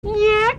• MAN CALLING YAK.wav
MAN_CALLING_YAK_MSR.wav